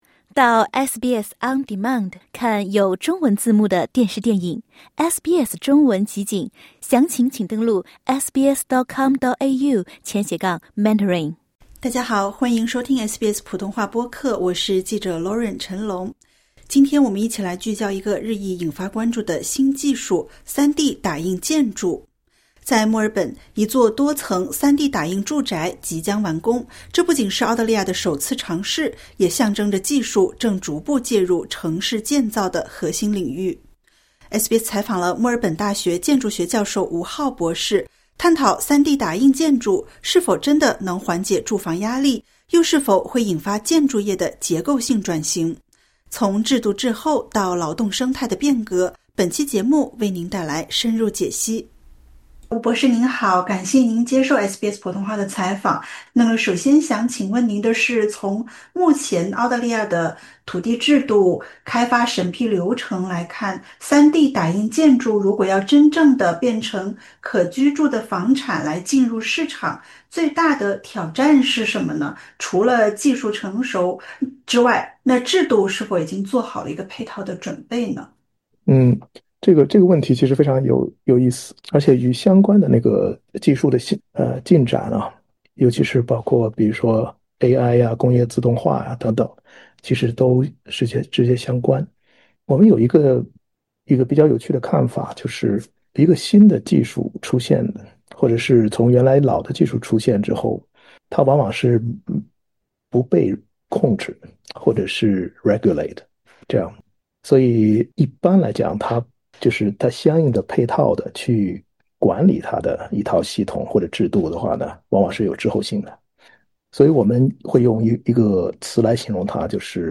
专家表示，3D打印建筑虽然在技术层面仍需进一步发展与验证，但建筑及相关行业已经需要开始做好准备，迎接新技术带来的变革。点击 ▶ 收听完整采访。